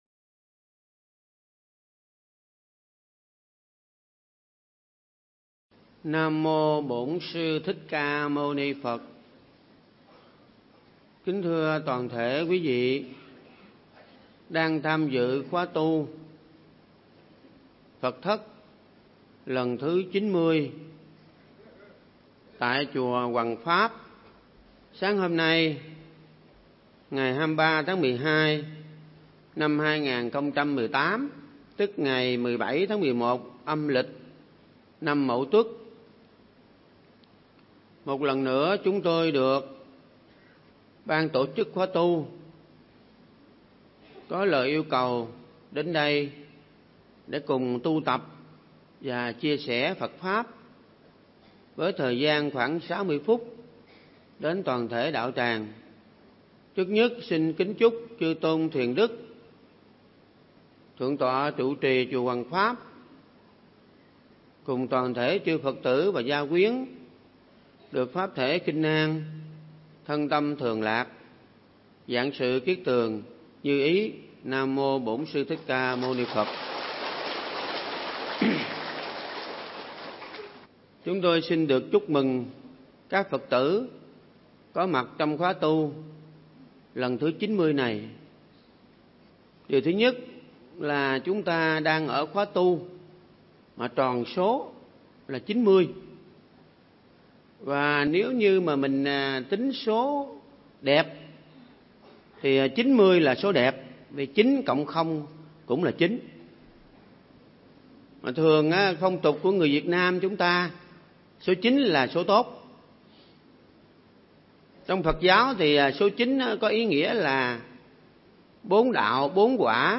Mp3 Thuyết Giảng Hỷ xã hay Xỉ Vả